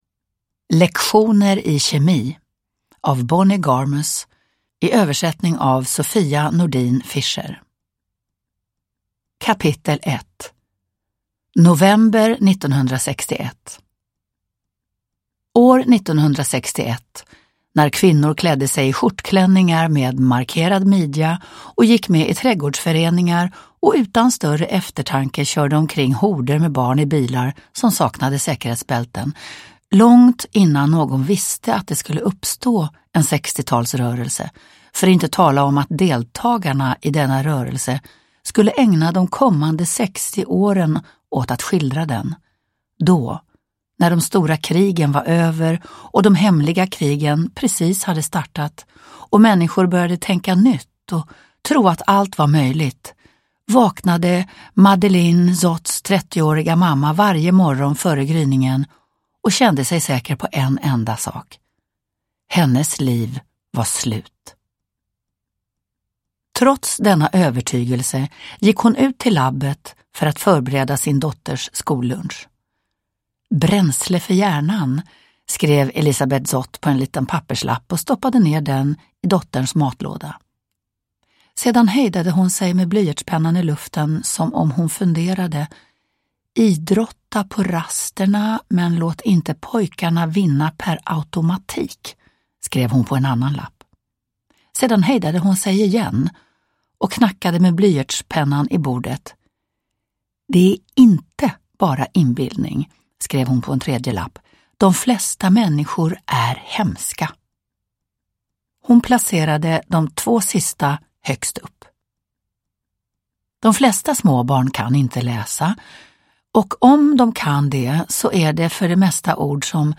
Lektioner i kemi – Ljudbok – Laddas ner
Uppläsare: Lena Endre